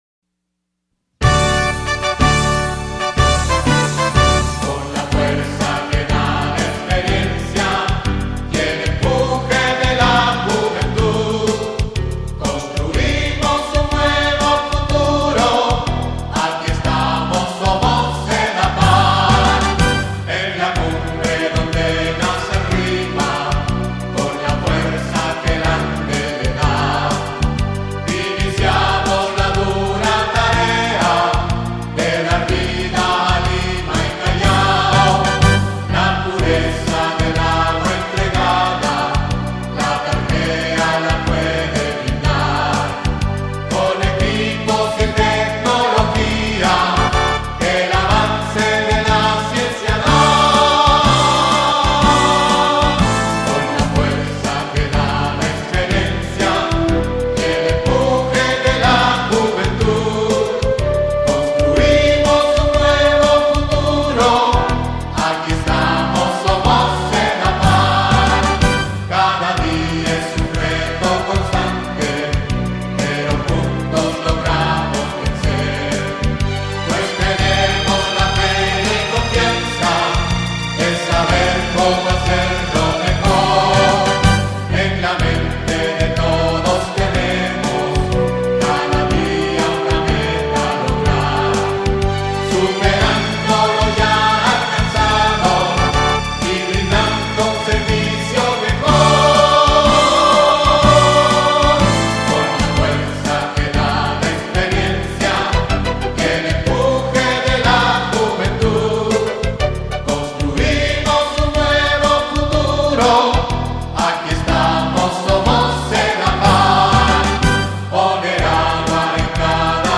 Himno Cantado (audio/mpeg)